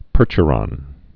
(pûrchə-rŏn, -shə-)